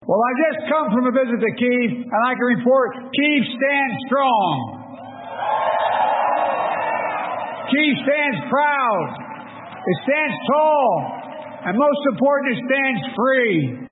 Amazwi kaMongameli Joe Biden.